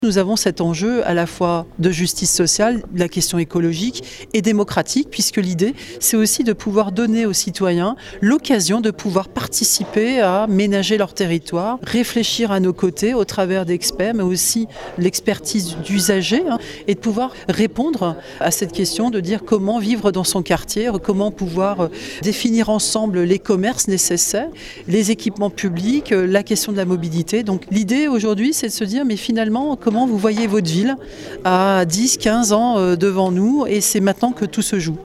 Les explications de Nora Segaud-Labidi, maire adjointe à la ville d’Annecy en charge de l’aménagement durable et de l’habitat